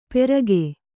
♪ perage